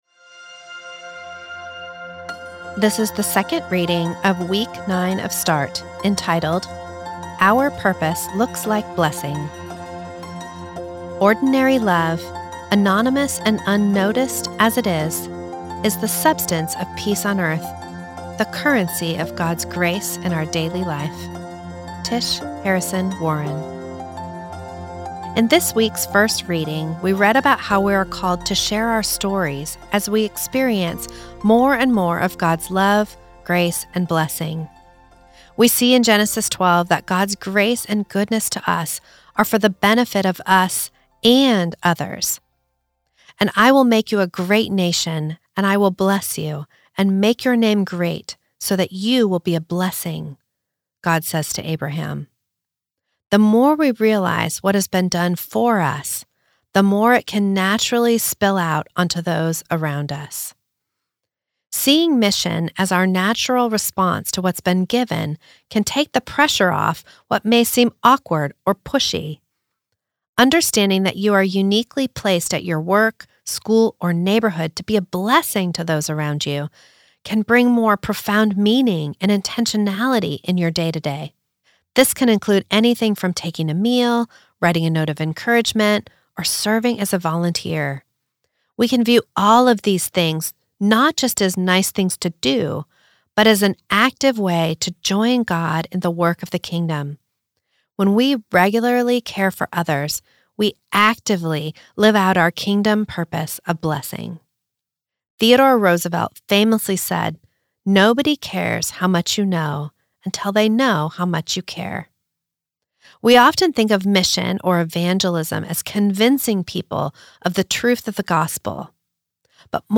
This is the audio recording of the second reading of week eight of Start, entitled Our Purpose Looks Like Blessing.